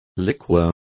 Complete with pronunciation of the translation of liqueur.